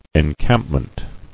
[en·camp·ment]